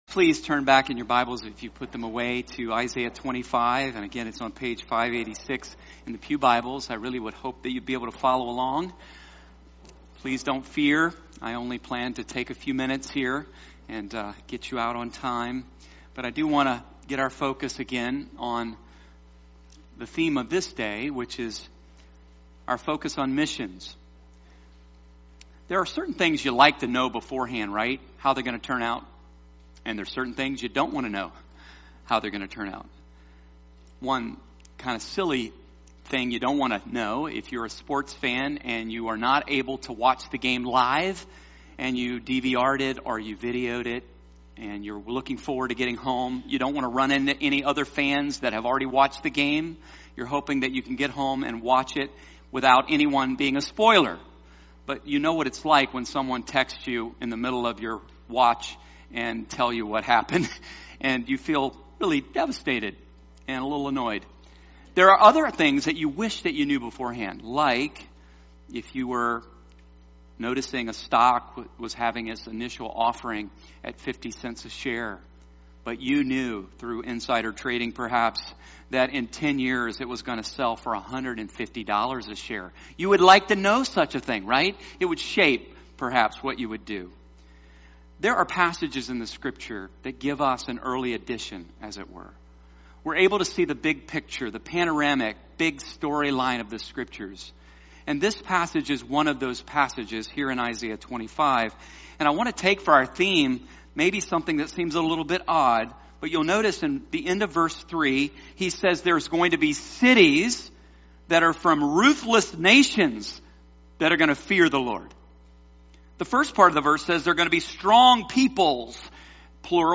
Sermons | East Brandywine Baptist Church